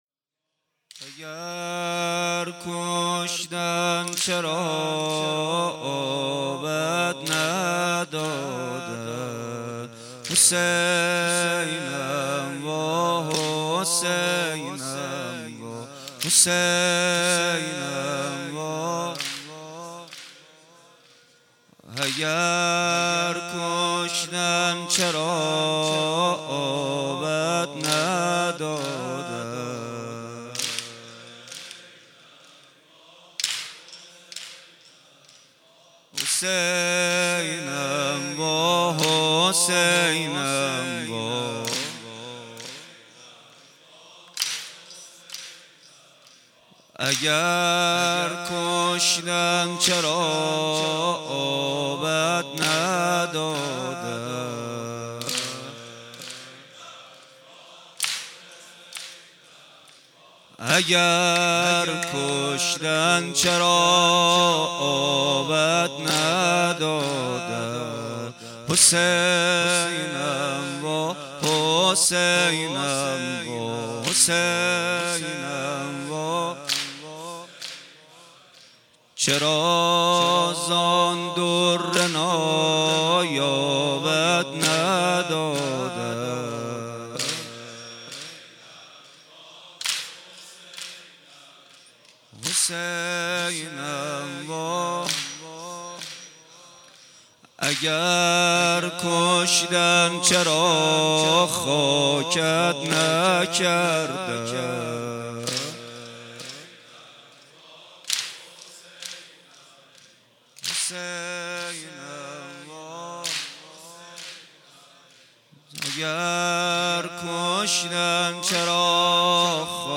کربلایی محمد حسین پویانفر
وفات حضرت زينب (س)